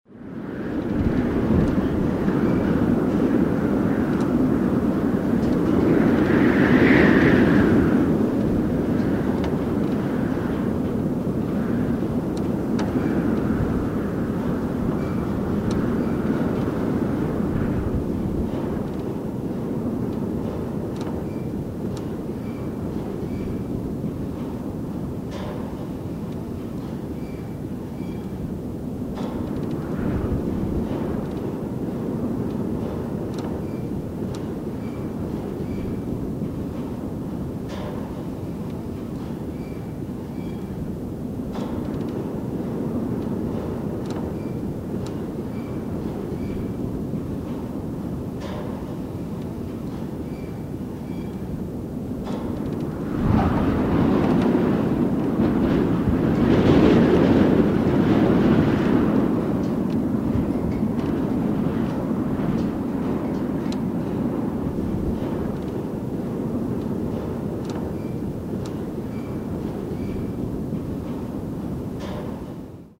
Звуки ветра
На этой странице собраны звуки ветра: от нежного шелеста листвы до мощных порывов в горах.